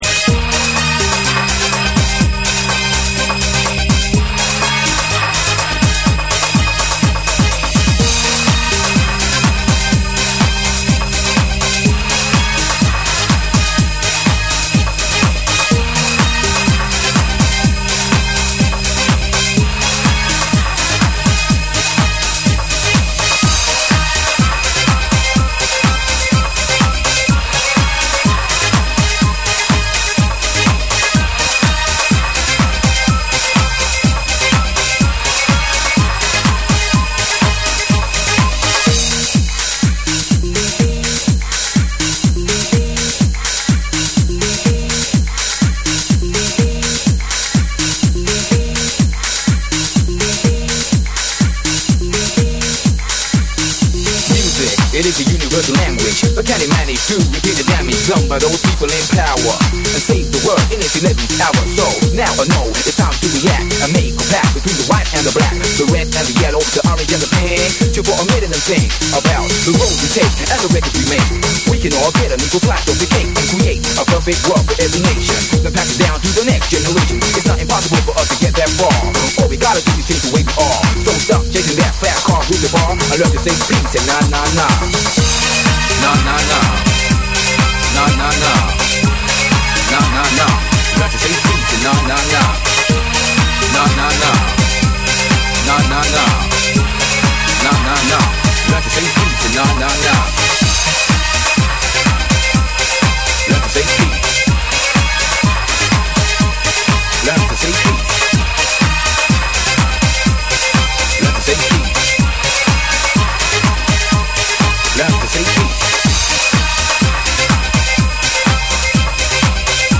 TECHNO & HOUSE / BREAKBEATS / ORGANIC GROOVE